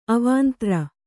♪ avāntra